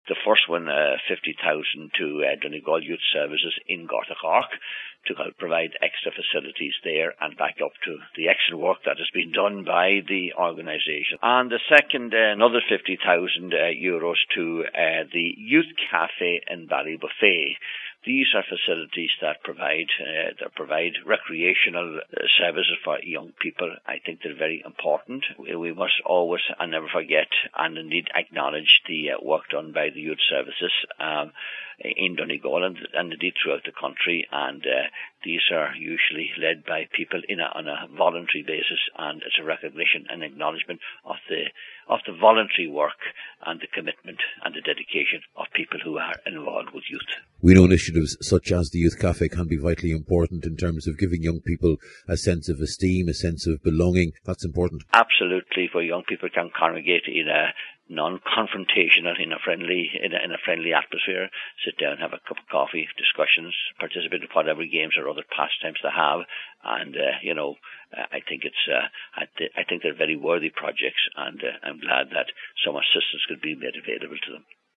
Junior Minister Dinny McGinley says it’s a significant allocation for two very important projects……….